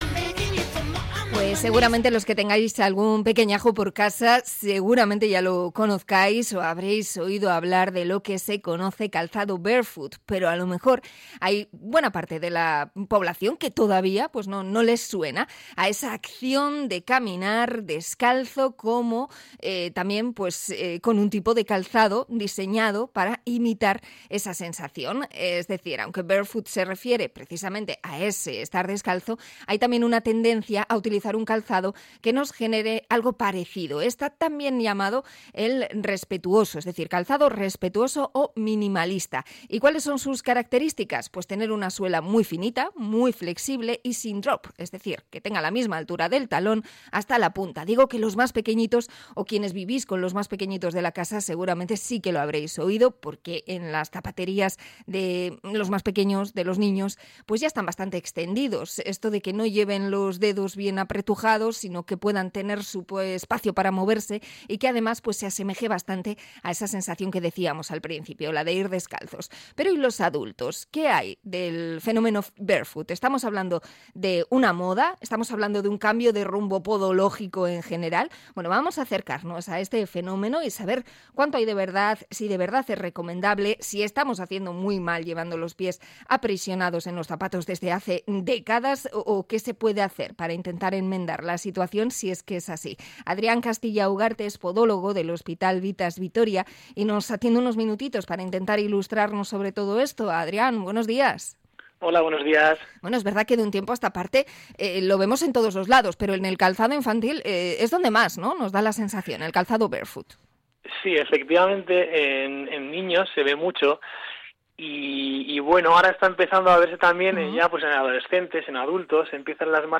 Entrevista a podólogo por la moda del 'barefoot'